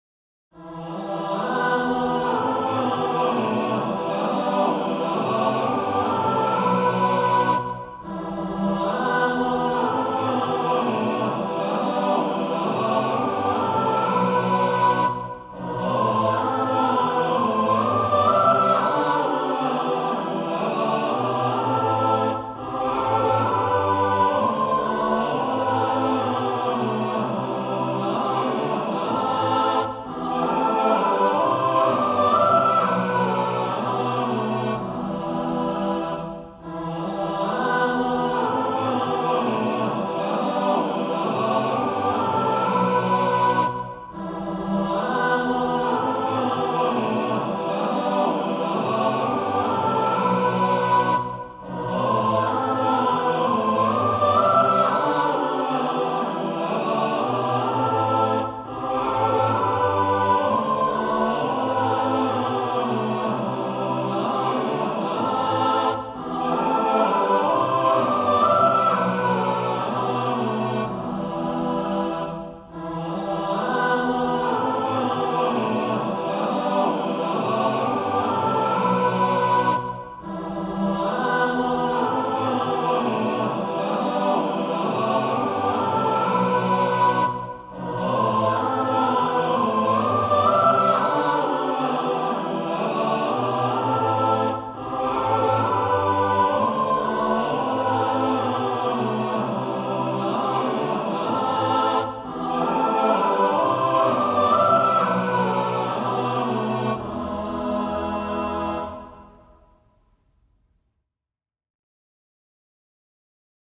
Het moet in 1985 geweest zijn dat ik bij wijze van oefening een arrangement gemaakt heb van het Kerstlied "God rest you merry gentlemen” voor vierstemmig koor.
Hierbij naast de bladmuziek voor koor, ook een synthetische